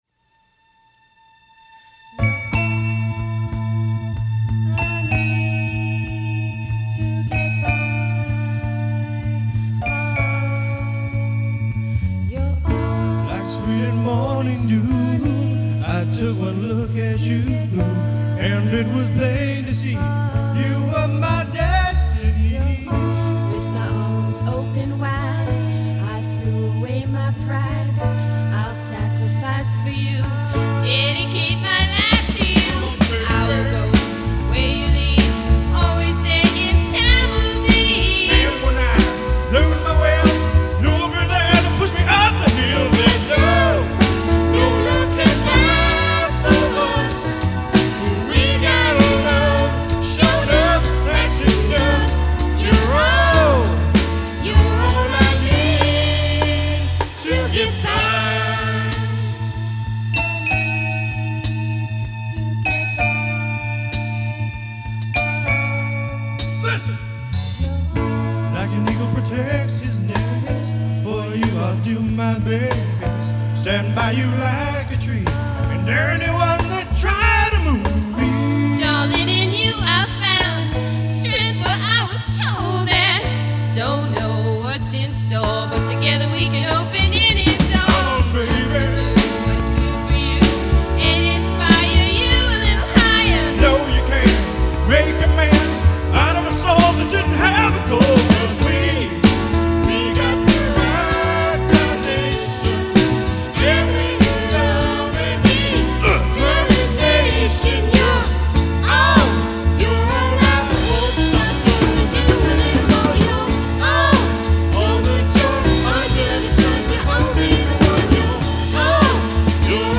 45RPM mix
I produced the music and played all the instruments